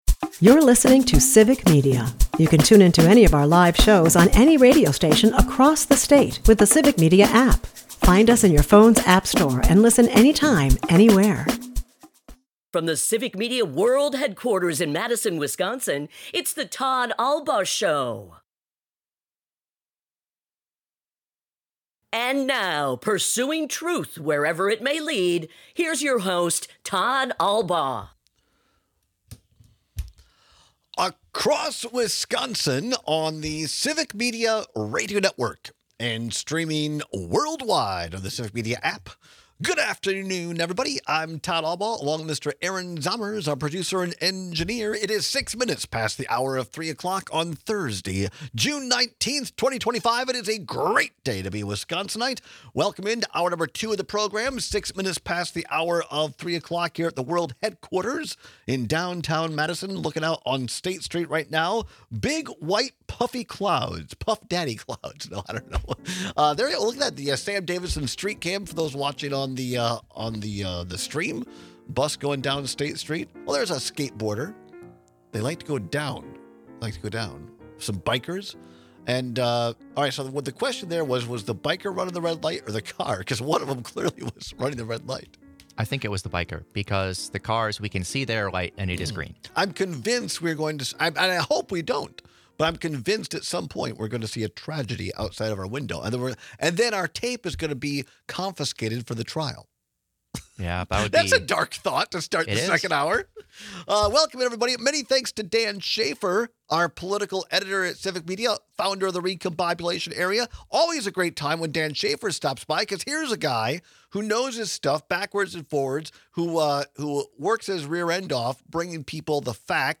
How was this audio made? We take your calls on which of the crawlies is creepiest to you.&nbsp